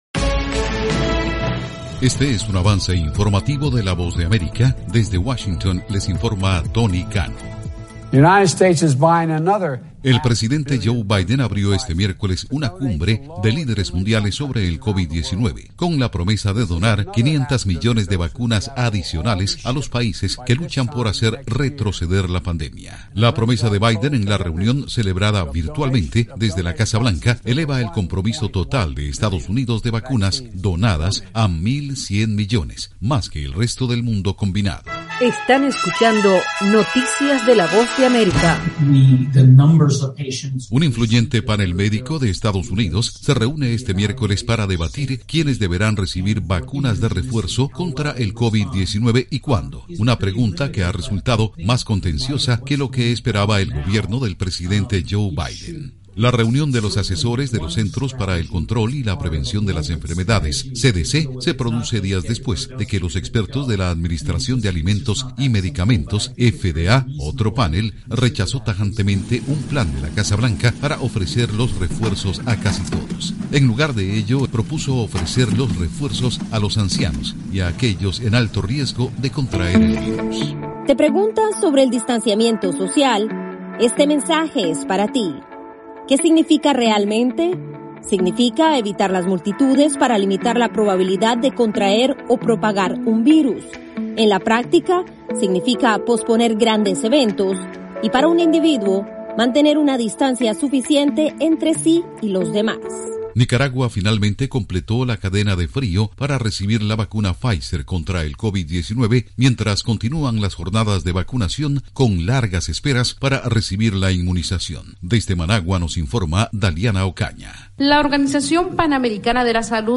AudioNoticias
Cápsula informativa de tres minutos con el acontecer noticioso de Estados Unidos y el mundo.